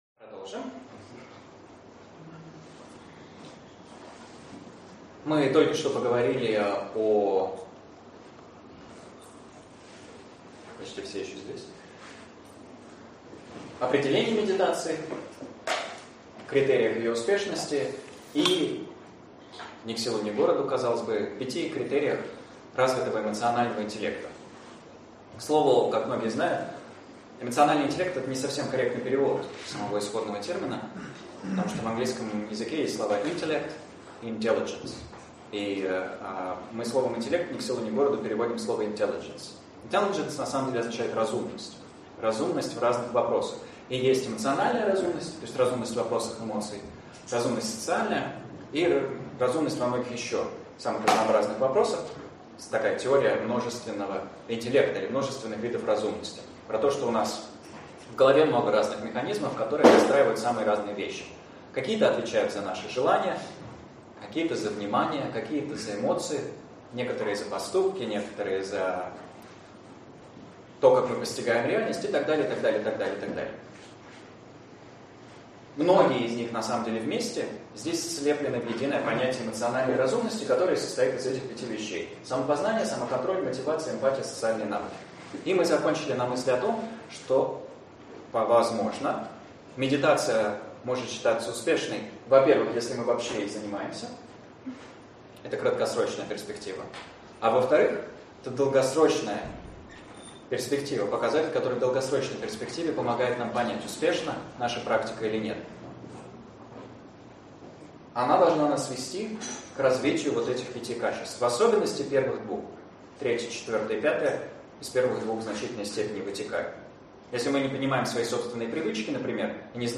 Аудиокнига Осознанность и дыхание. Часть 2 | Библиотека аудиокниг